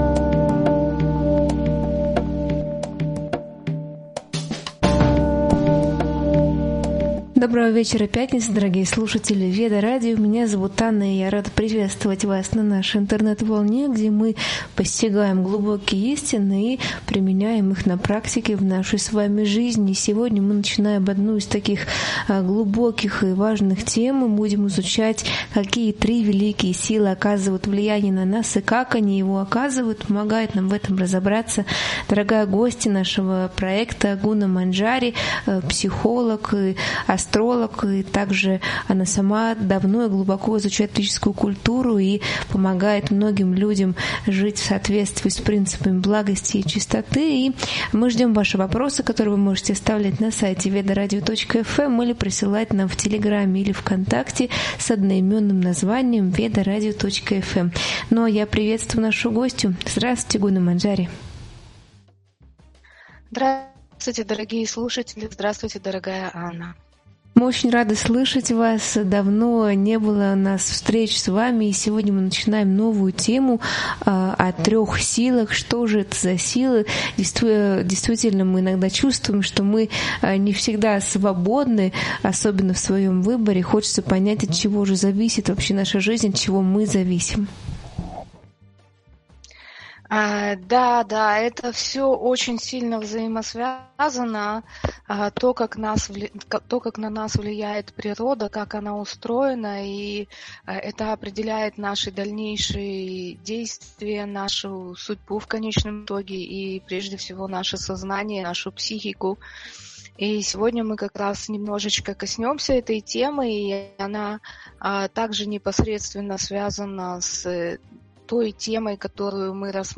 Эфир посвящён влиянию трёх гун материальной природы — благости, страсти и невежества — на сознание и судьбу человека. Обсуждается, как эти энергии формируют наши мысли, поведение и жизненные обстоятельства. Говорится о природе страданий, законе кармы и свободе выбора.